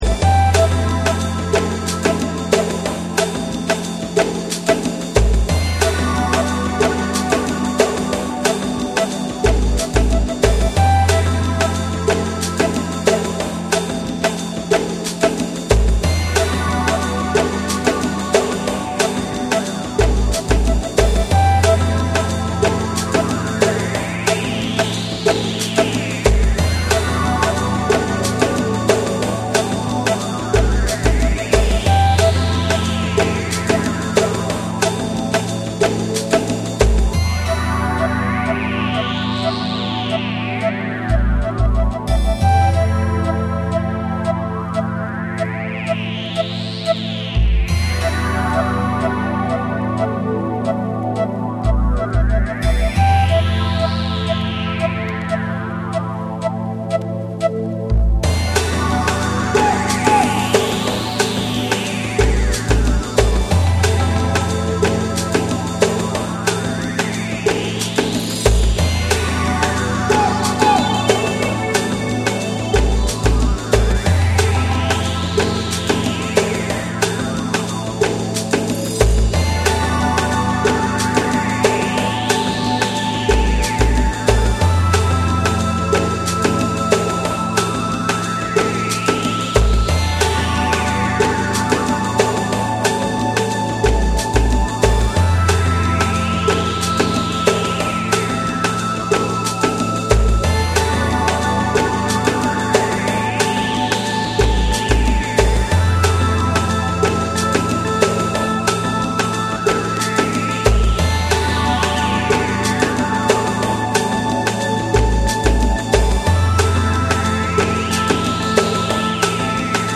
幻想的で浮遊感あるオーガニック・ブレイク
BREAKBEATS / ORGANIC GROOVE